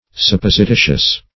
Supposititious \Sup*pos`i*ti"tious\, a. [L. suppositicus.